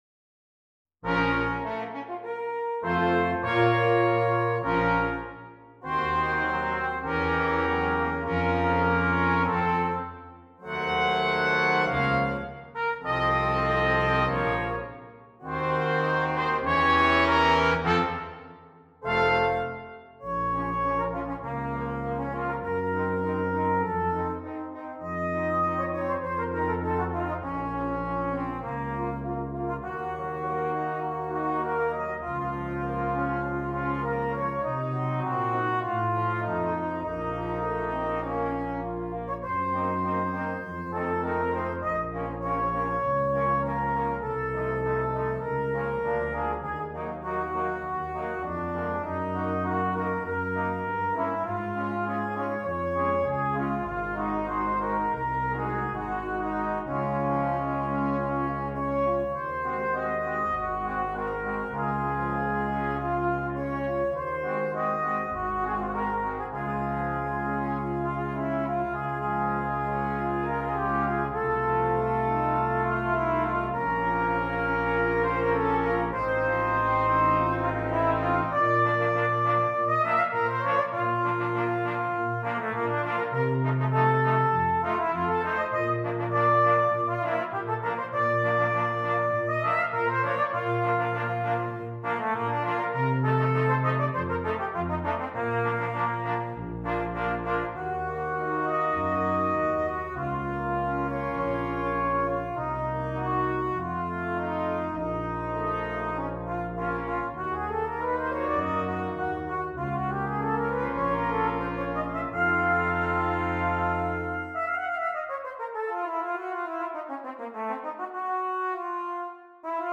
Brass Quintet and Solo Trumpet